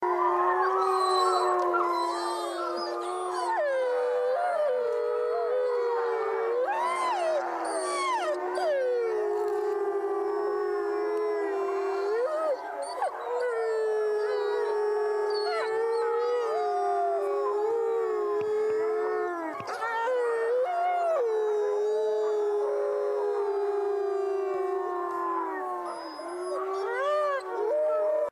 Bestand:Wolf howls.ogg
Wolf_howls.ogg.mp3